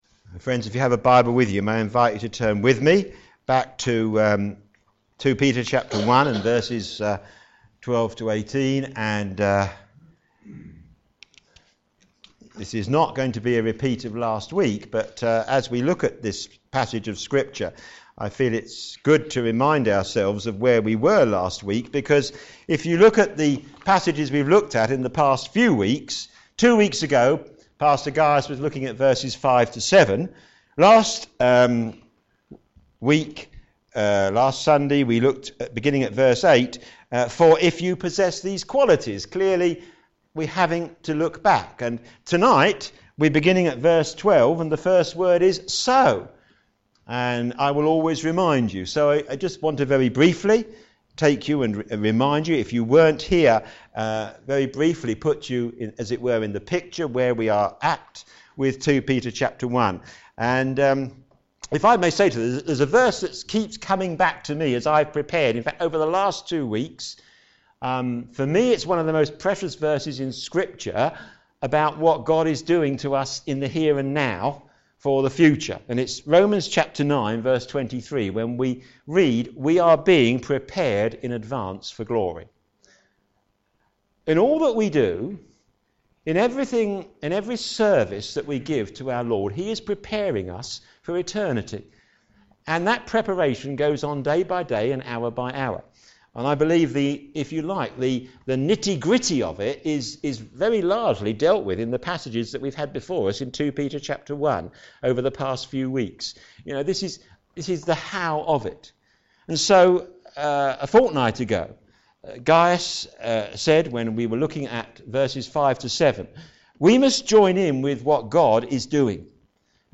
Media for p.m. Service on Sun 04th Oct 2015 18:30
Series: Standing for the Faith Theme: Know your Scripture - Apostolic Authority Sermon